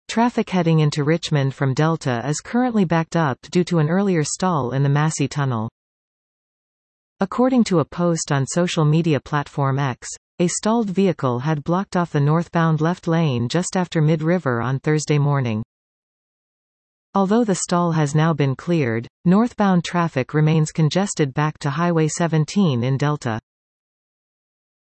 DriveBC Listen to this article 00:00:24 Traffic heading into Richmond from Delta is currently backed up due to an earlier stall in the Massey Tunnel.